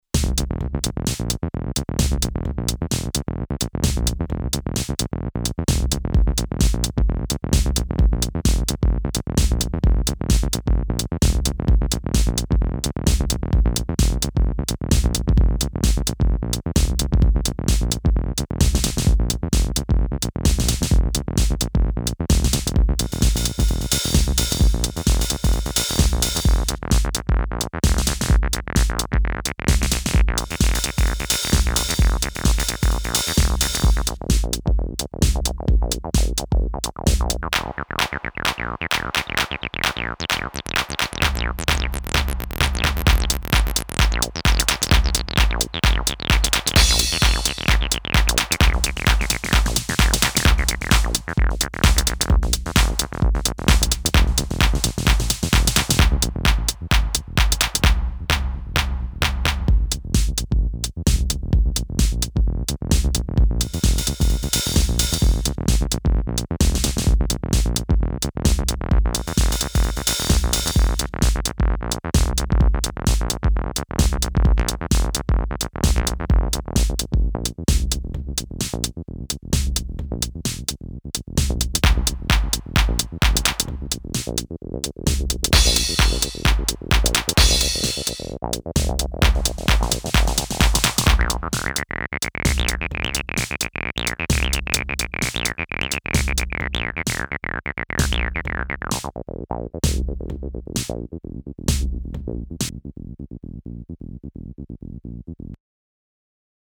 Bassbot with Roland Tr909